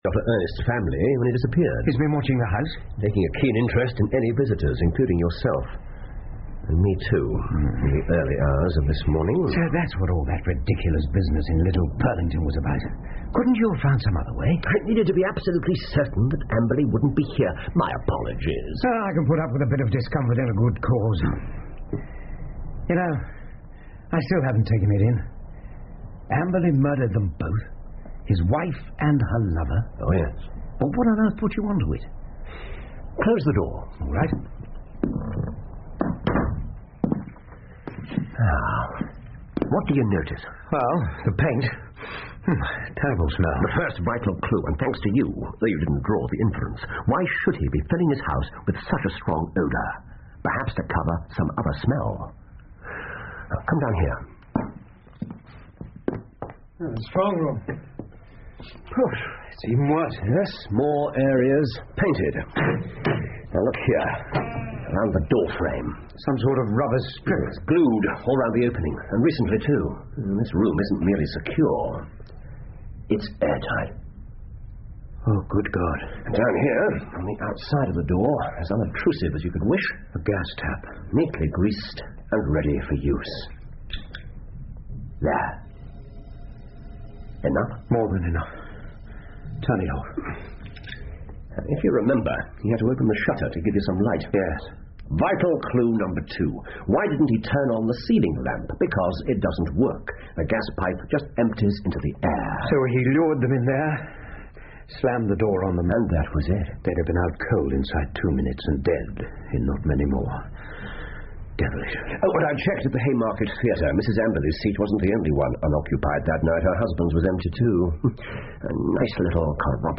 福尔摩斯广播剧 The Retired Colourman 8 听力文件下载—在线英语听力室